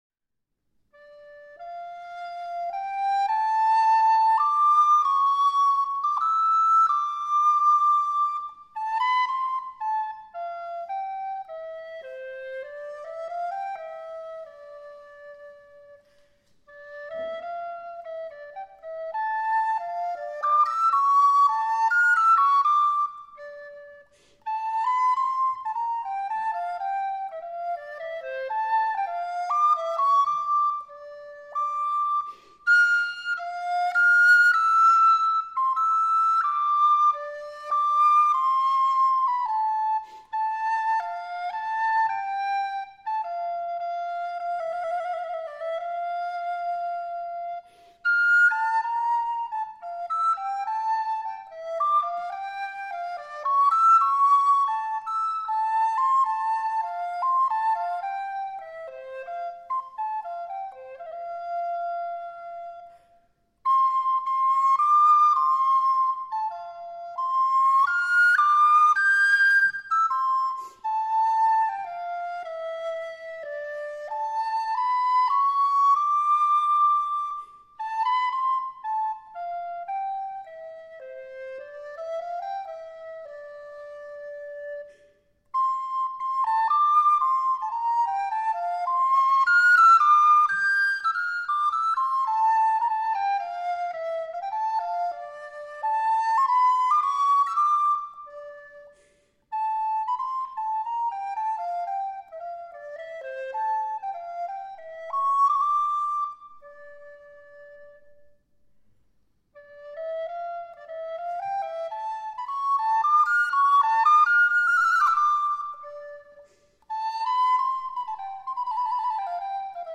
cello
piano) — voorjaarseditie 2018.